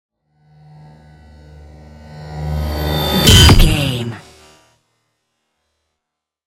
Sci fi whoosh to hit shot
Sound Effects
Atonal
dark
futuristic
intense
tension
woosh to hit